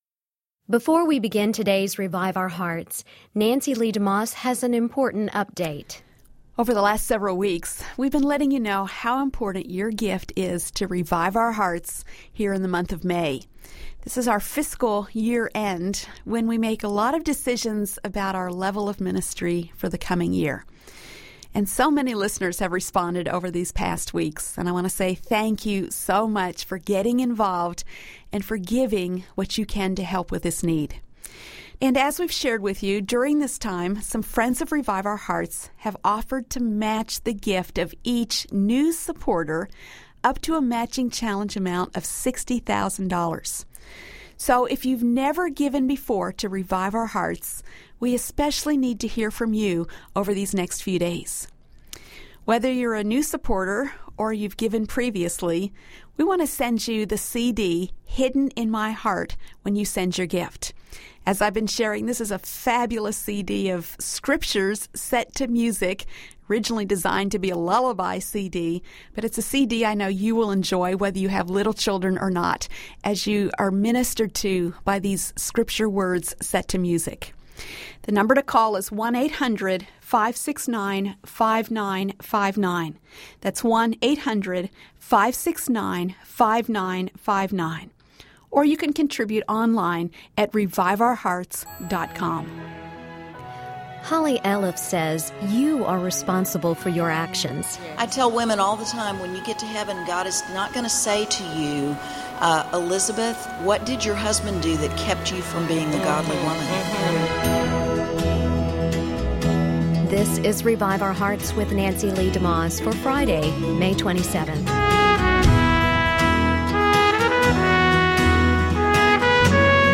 You want to honor God in the way you handle issues like these. Listen to the True Woman '10 Panel Discussion.